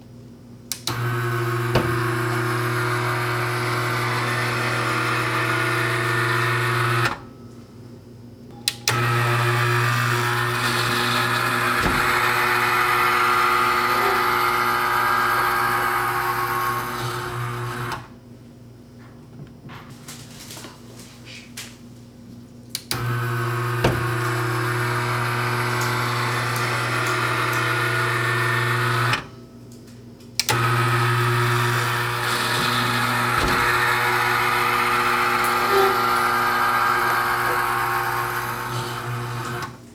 mechanicalLoop40s.wav